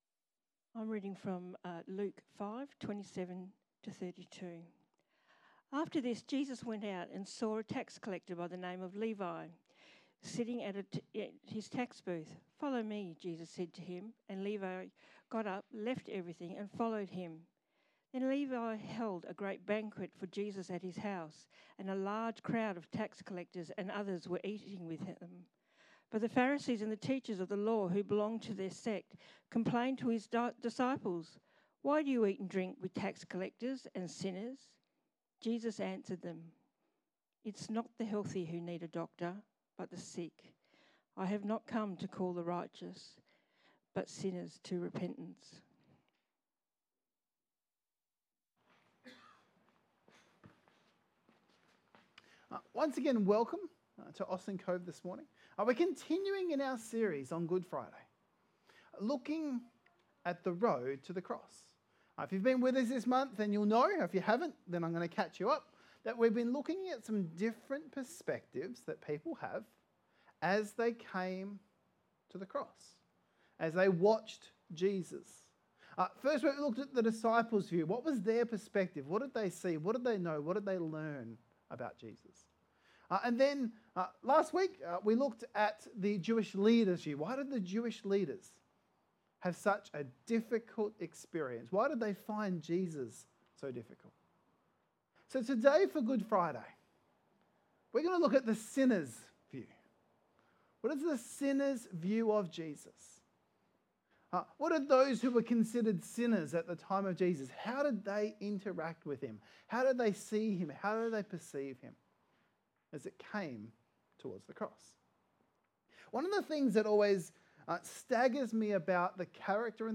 How did people that many labelled ‘sinners’ view Jesus…and why? A Good Friday Message.